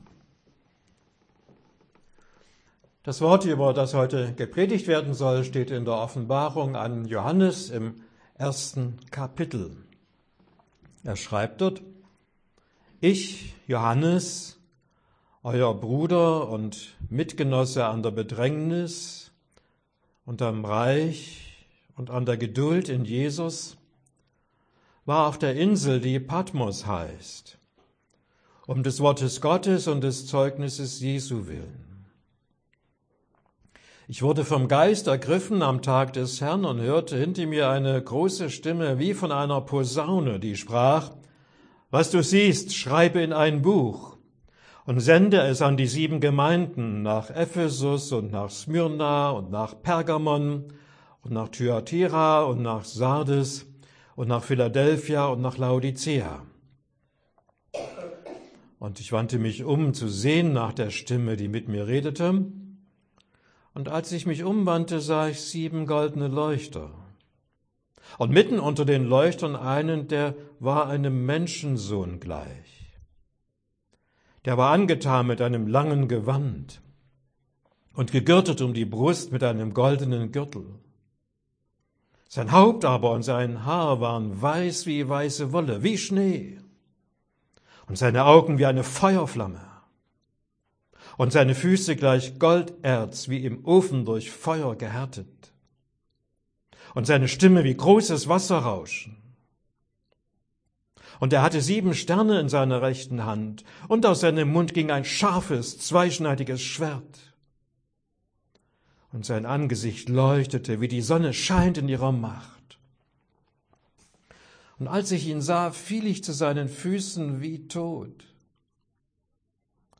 Predigt für den Letzten Sonntag nach Epiphanias